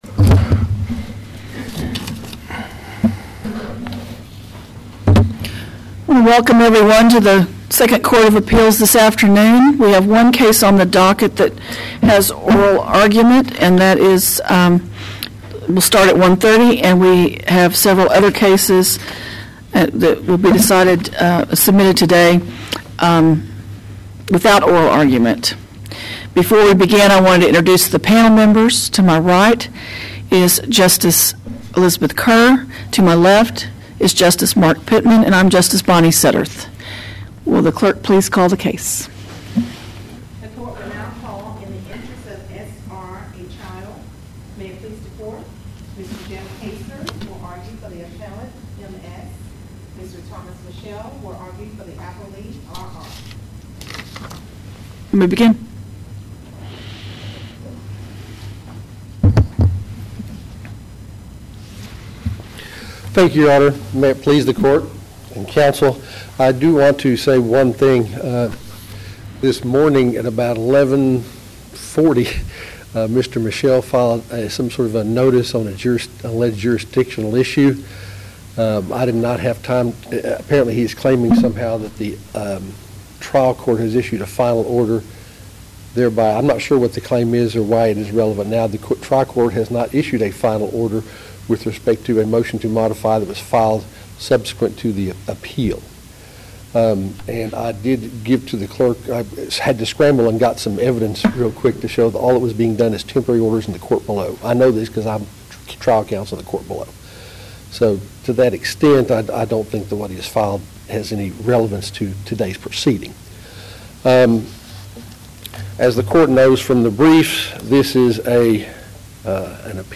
TJB | 2nd COA | Practice Before the Court | Oral Arguments | 2017